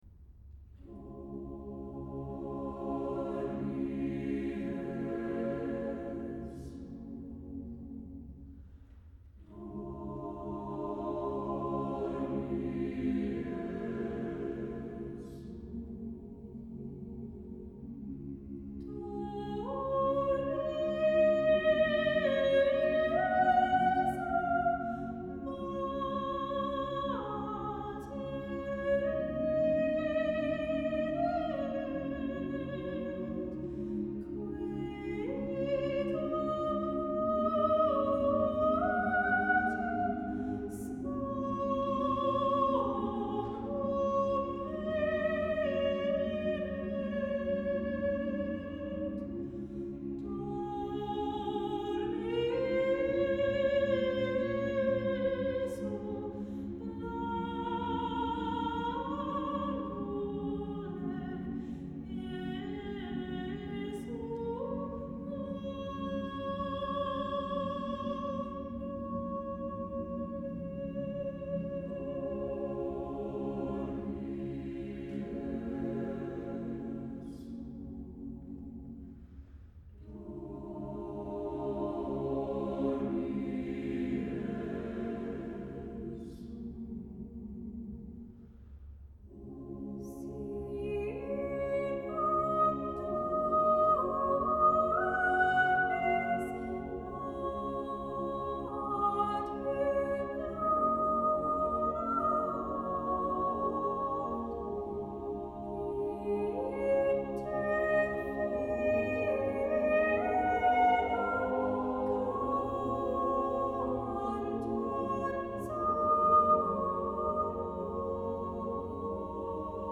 Oeuvres chorales composées entre 2010 et 2016.
saxophone soprano
Mais il y a ici la sérénité profonde que suggère la musique.